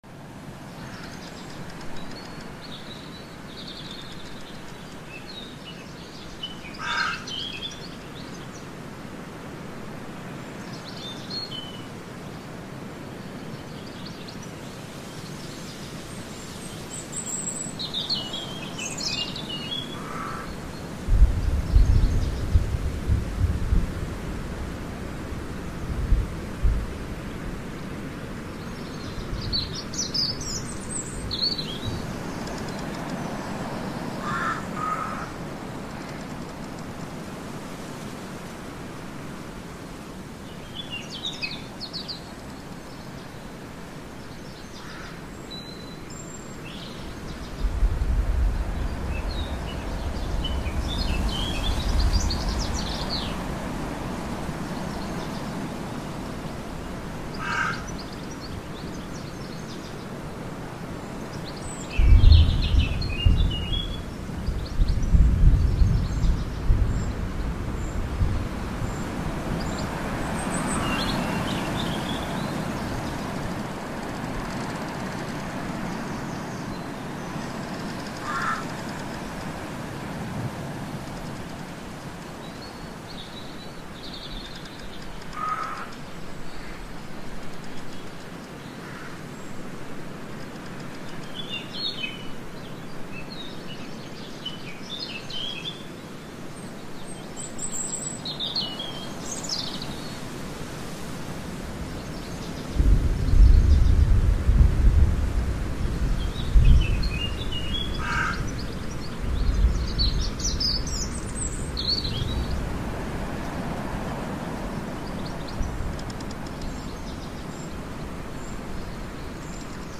دانلود آهنگ باد 6 از افکت صوتی طبیعت و محیط
دانلود صدای باد 6 از ساعد نیوز با لینک مستقیم و کیفیت بالا
جلوه های صوتی